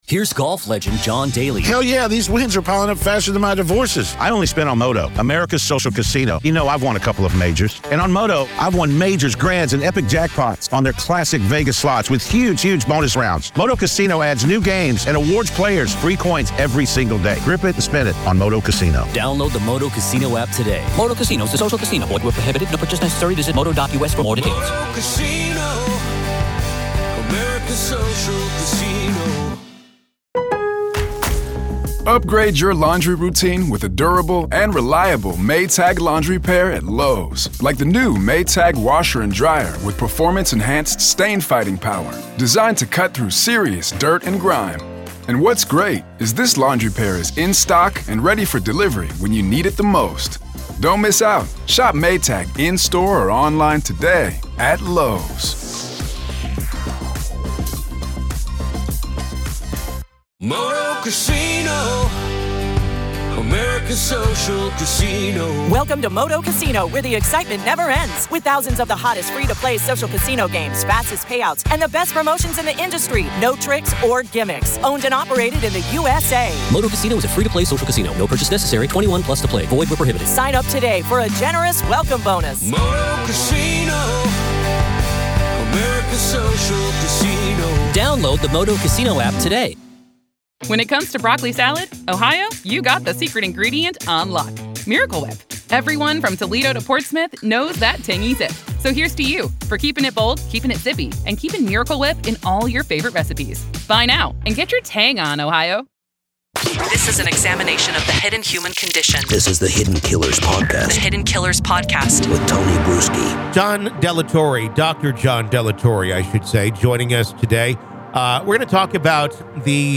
Tune in for an important conversation about the unseen struggles of the brave individuals who put their lives on the line to protect others.